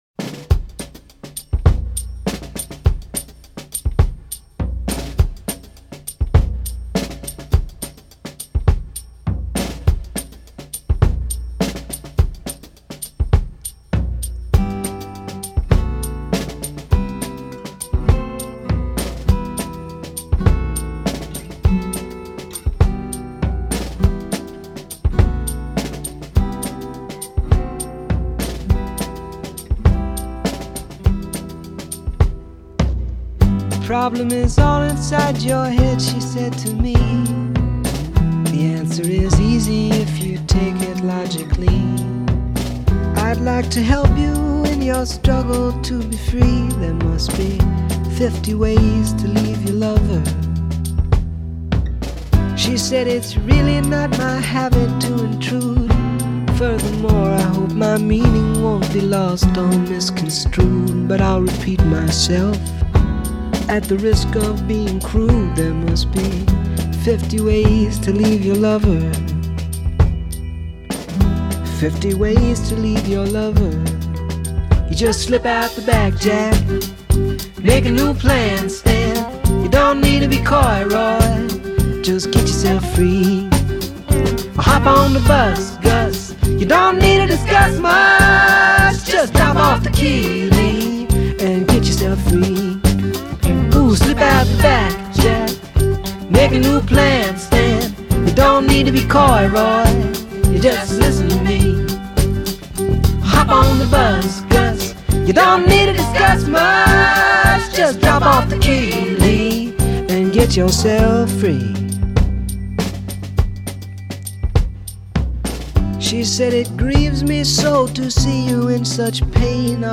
Жанр: Folk Rock, Folk, Pop